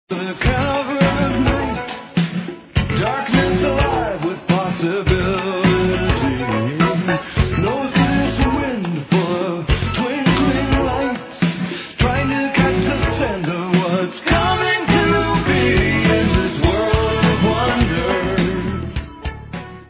sledovat novinky v kategorii Rock